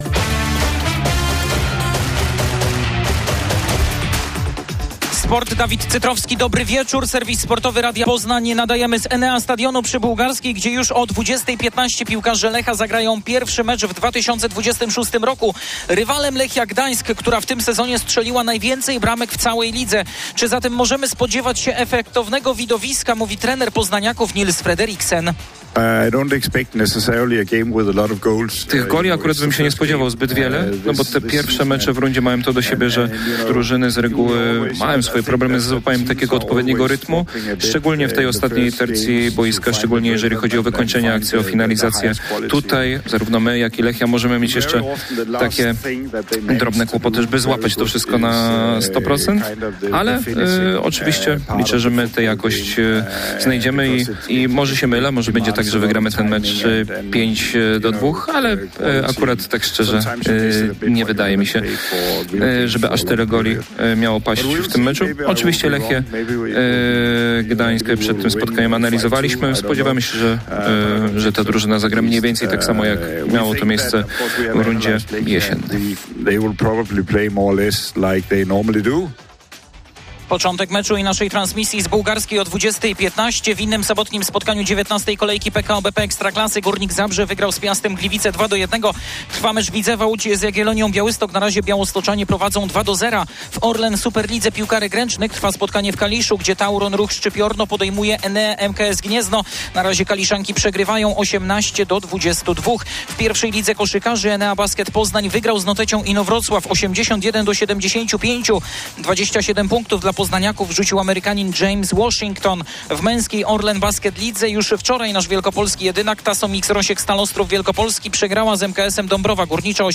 31.01.2026 SERWIS SPORTOWY GODZ. 19:05
Serwis sportowy prosto z Enea Stadionu przed meczem Lecha z Lechią Gdańsk. Ponadto mówimy o sportach zimowych, tenisie i koszykówce.